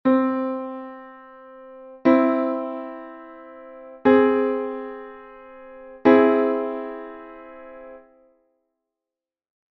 - 5ª Aumentada: formado por unha 3ª Maior e unha 5ª Aumentada.